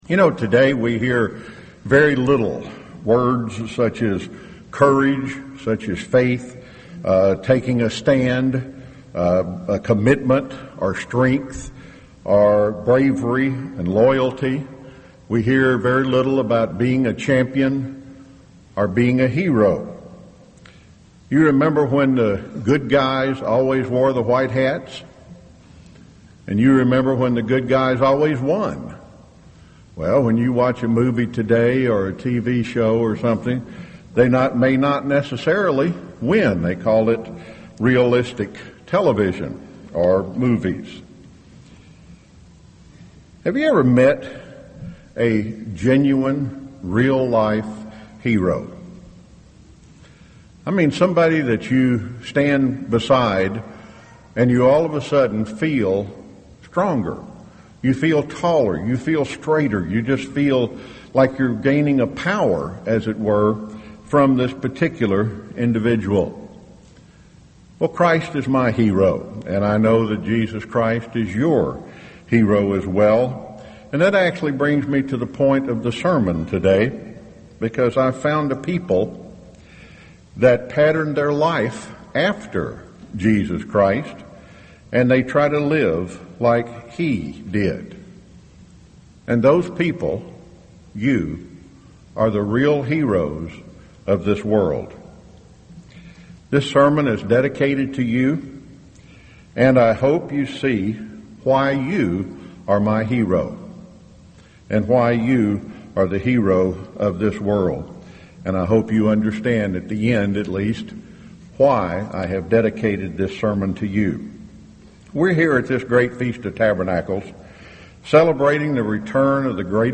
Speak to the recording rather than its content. This sermon was given at the Jekyll Island, Georgia 2015 Feast site.